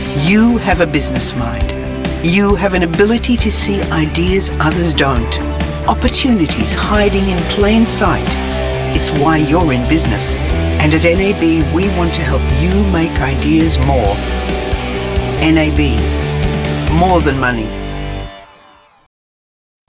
I have a dedicated professionally equipped home studio for high quality sound and quick efficient turn around. My voice overs are confident, warm, conversational, expressive, engaging, versatile and clear.
englisch (australisch)
Sprechprobe: Industrie (Muttersprache):